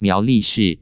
::: 請輸入欲查詢漢字、注音或拼音（20字為限） 顯示聲調符號 不顯示聲調符號 查詢結果 查詢文字 苗栗市 注音 ㄇㄧㄠˊ 。 ㄌㄧˋ 。 ㄕˋ 漢語拼音 miáo lì shì 通用拼音 miáo lì shìh 注音二式 miáu lì shr̀ 威妥瑪拼音 miao 2 li 4 shih 4 耶魯拼音 myáu lì shr̀ 發音 使用說明 您輸入一個以上的中文字，多音字之發音以人工智慧技術判斷，結果僅供參考